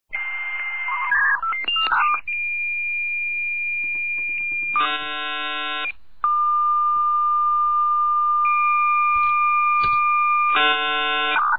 modem1.mp3